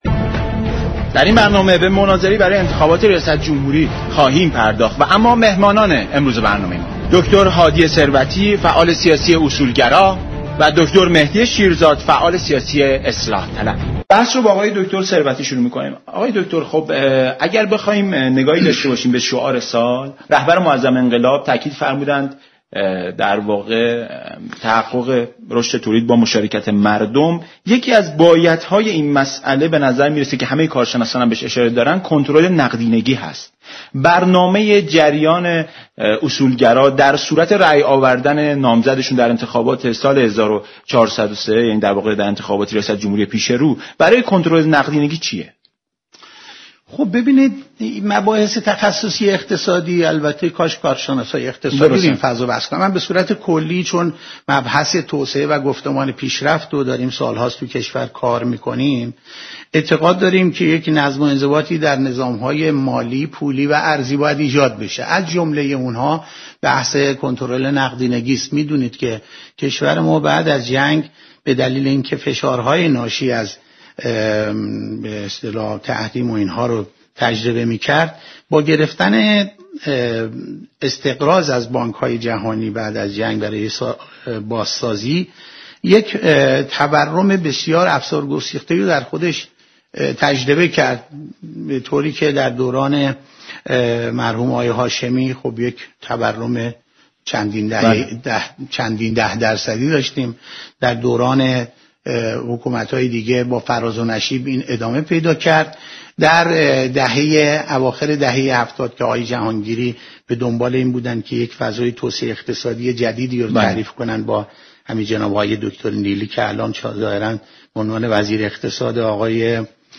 در آستانه برپایی چهاردهمین دوره انتخابات ریاست جمهوری برنامه ایران امروز با حضور كارشناسان به بحث و مناظره در باب انتخابات پرداخته است